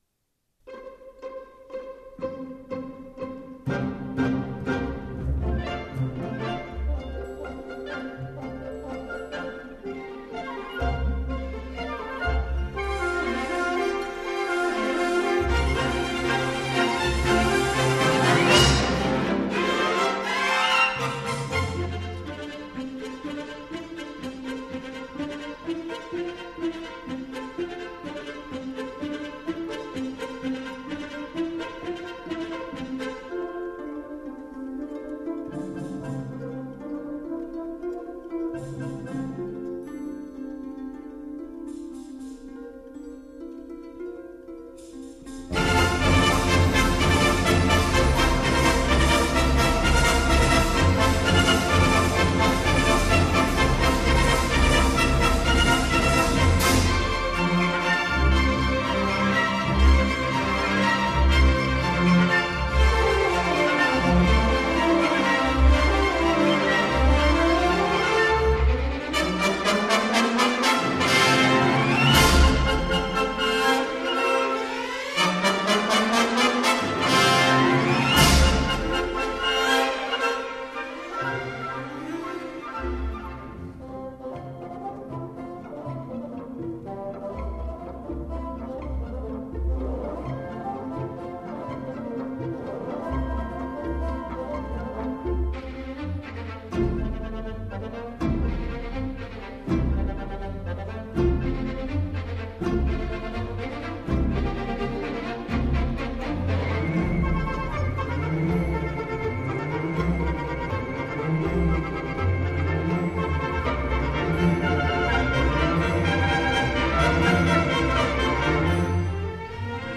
的音色艷麗無比，充滿了陽光